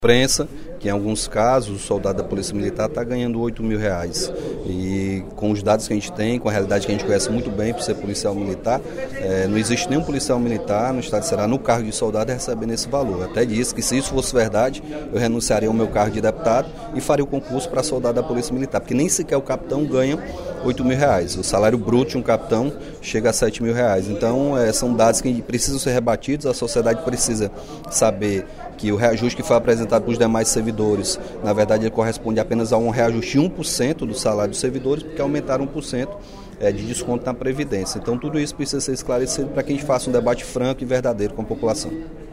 O deputado Capitão Wagner (PR) corrigiu informação dada pelo governador Camilo Santana sobre a média salarial de um soldado da Polícia Militar do Ceará. O pronunciamento foi feito durante o primeiro expediente da sessão plenária desta quarta-feira (08/02).